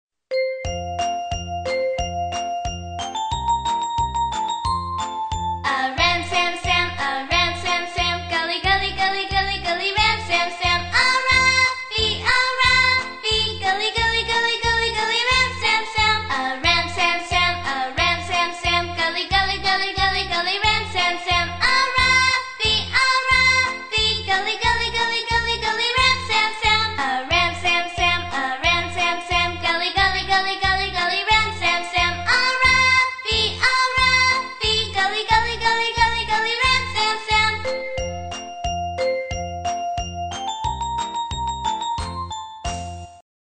在线英语听力室英语儿歌274首(MP3+文本) 第1期:A Ram Sam Sam的听力文件下载,收录了274首发音地道纯正，音乐节奏活泼动人的英文儿歌，从小培养对英语的爱好，为以后萌娃学习更多的英语知识，打下坚实的基础。